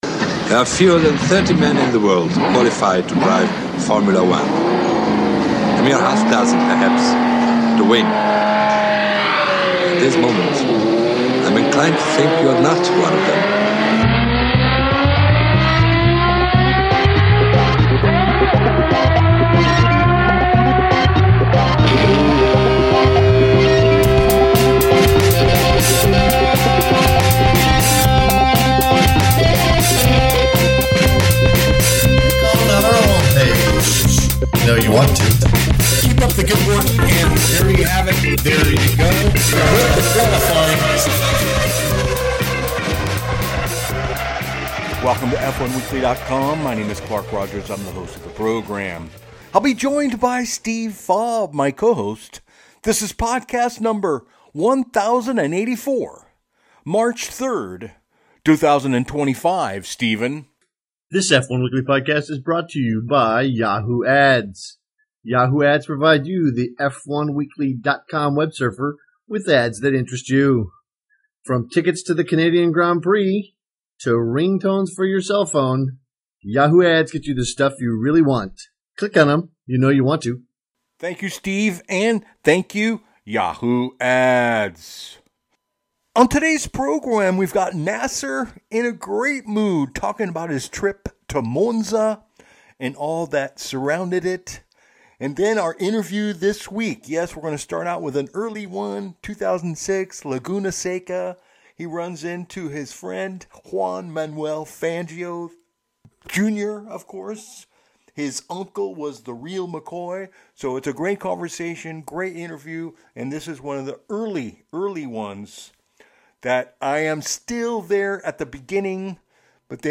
giving us another early classic Interview.